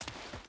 Dirt Walk 3.ogg